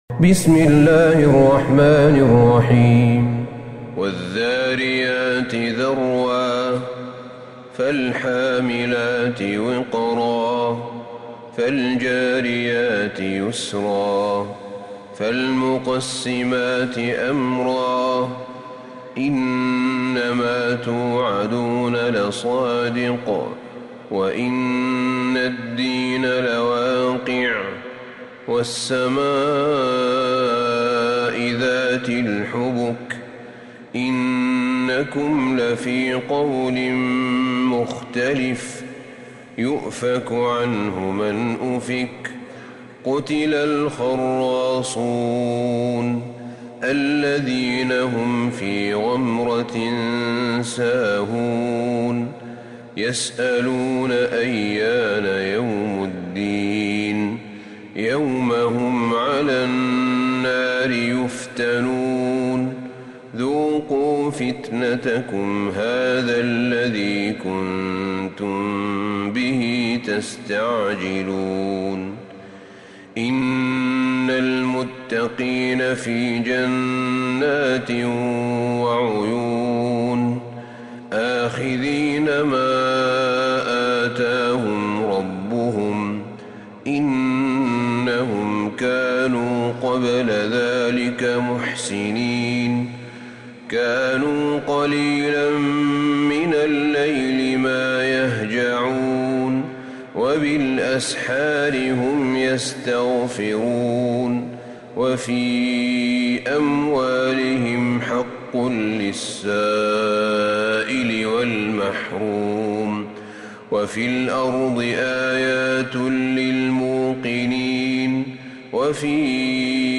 سورة الذاريات Surat AdhDhariyat > مصحف الشيخ أحمد بن طالب بن حميد من الحرم النبوي > المصحف - تلاوات الحرمين